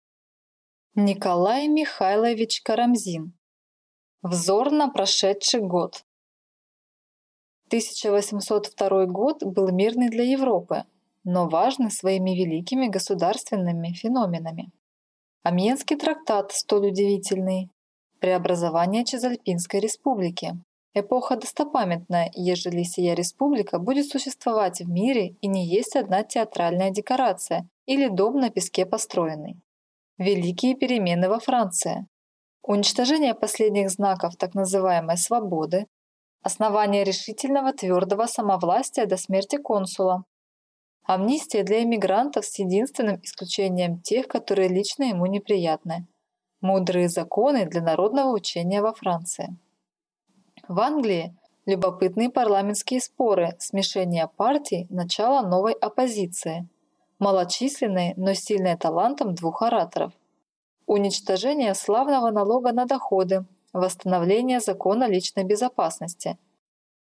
Аудиокнига Взор на прошедший год | Библиотека аудиокниг